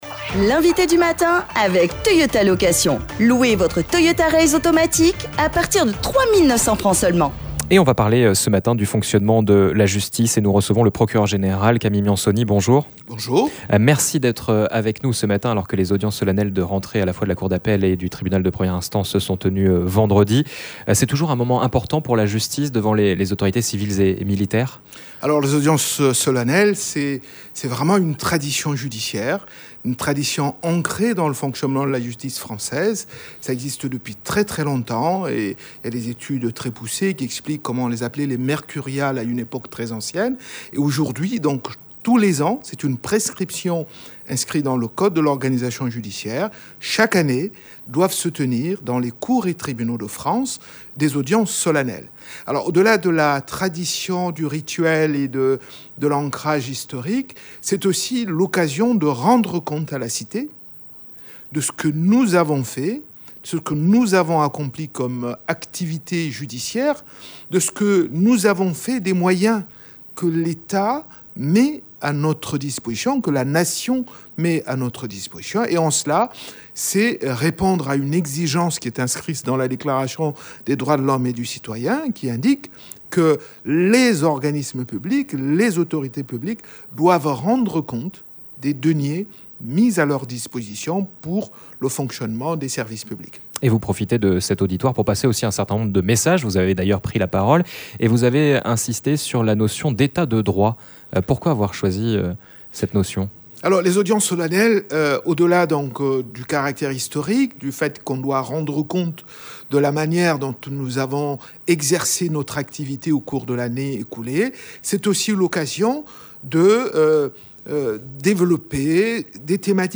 Notre invité du matin est le procureur général, pour évoquer la rentrée judiciaire.